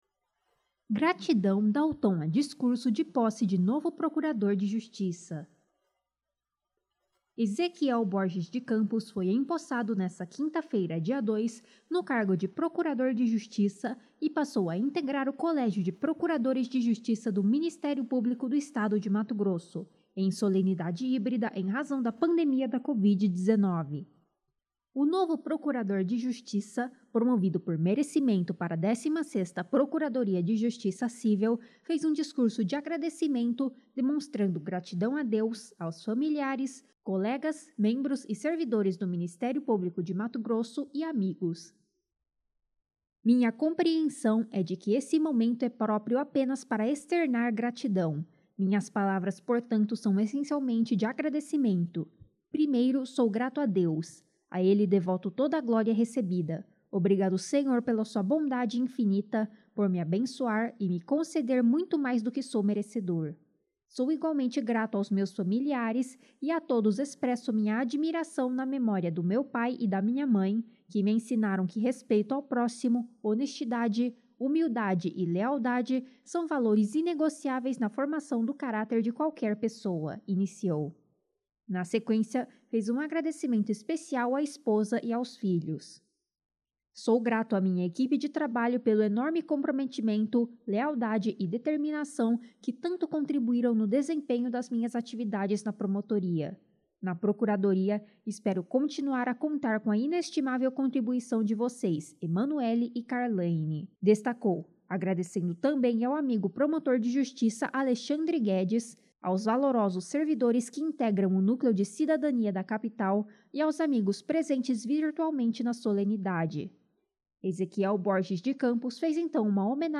CERIMÔNIA VIRTUAL
Gratidão dá o tom a discurso de posse de novo procurador de Justiça